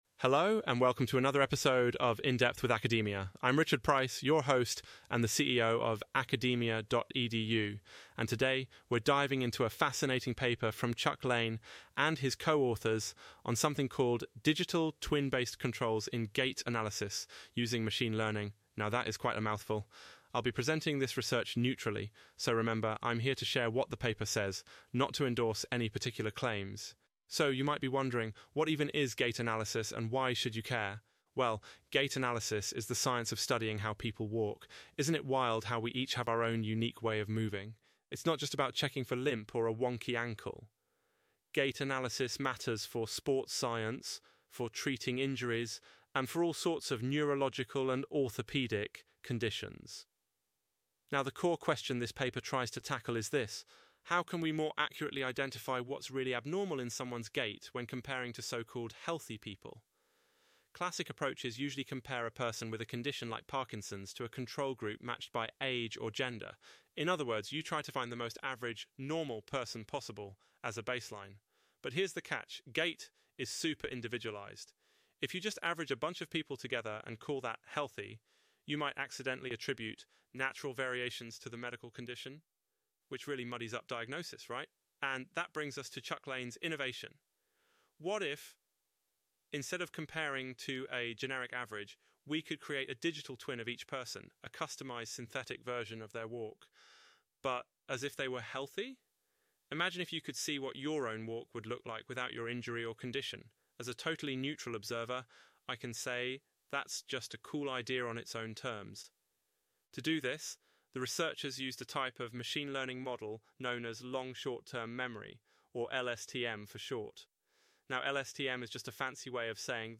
Automated Audio Summary